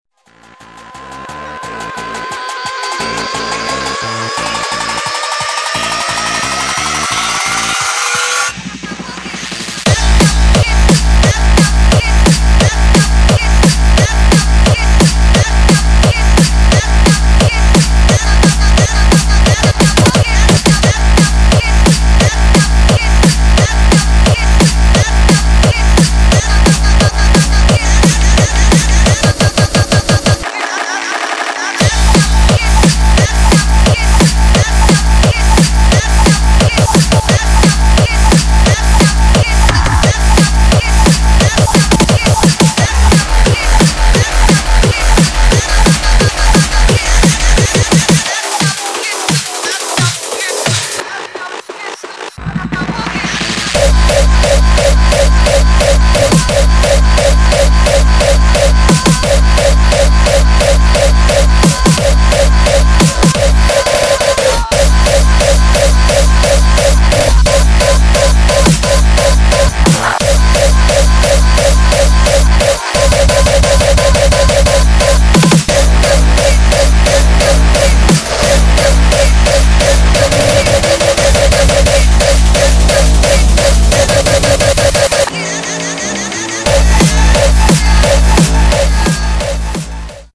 [ HARDCORE ]